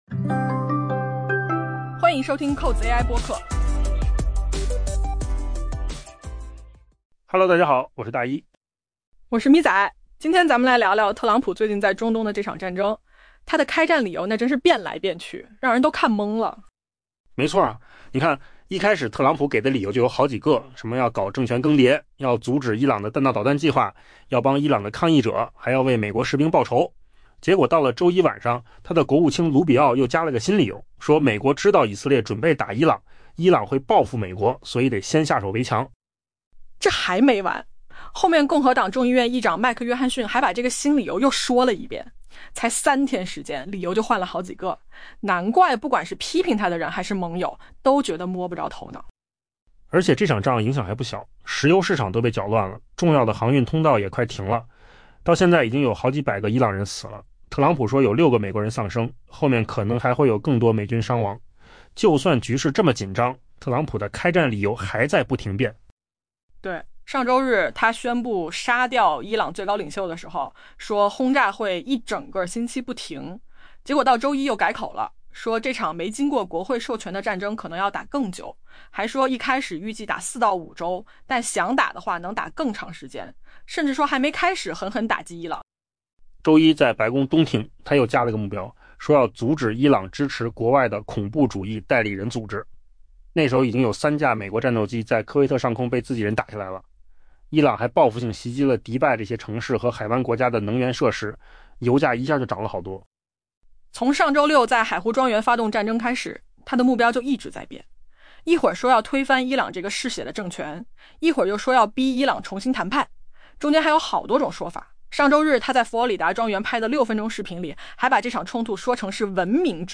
AI 播客：换个方式听新闻 下载 mp3 音频由扣子空间生成 特朗普为美国卷入另一场中东战争列举了数个理由：政权更迭、阻止德黑兰的弹道导弹计划、帮助抗议者以及为美国士兵的死亡复仇。